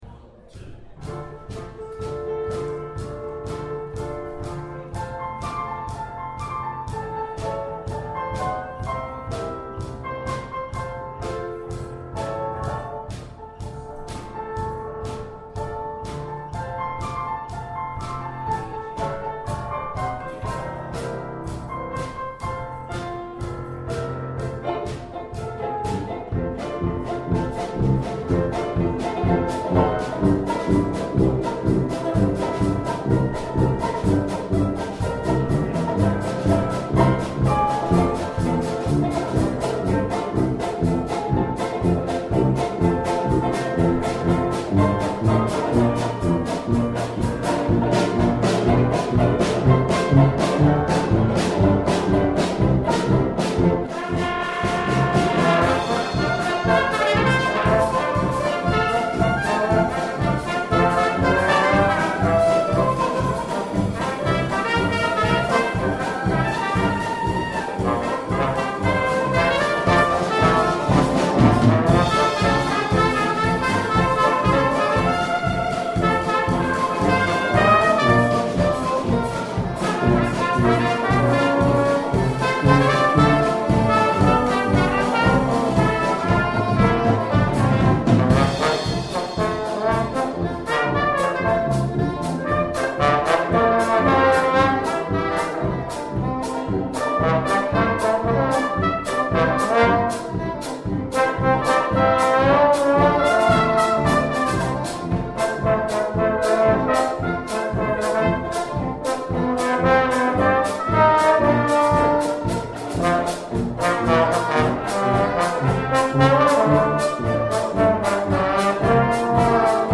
Piano
Banjo
Trombone
SETTING: Jam sessions and practice sessions. There are no studio recordings in this list.
We added as much improv as appropriate.